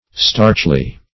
starchly - definition of starchly - synonyms, pronunciation, spelling from Free Dictionary Search Result for " starchly" : The Collaborative International Dictionary of English v.0.48: Starchly \Starch"ly\, adv. In a starched or starch manner.